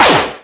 NFF-laser-gun-03.wav